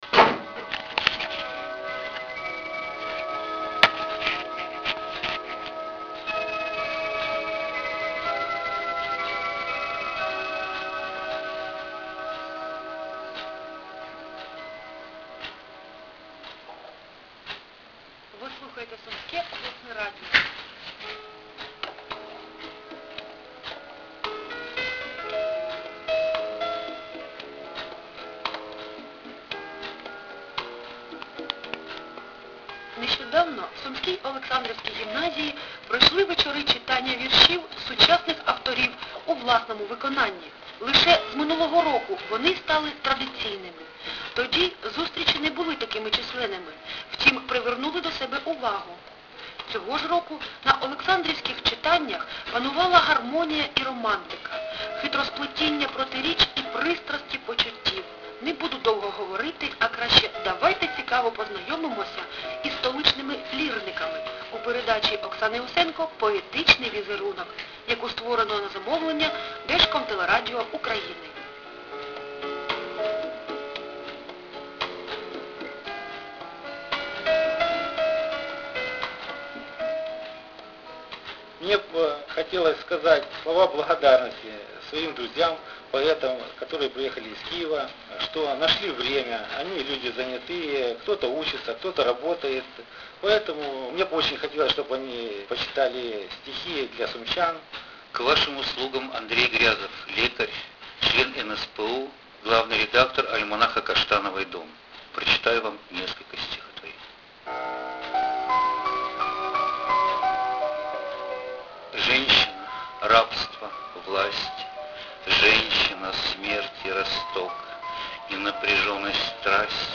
Дорогие друзья, эту запись я делал на диктофон на кухне прямо с приёмника, так что, если что не так, "звиняйте".